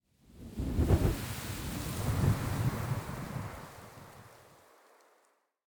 housewind02.ogg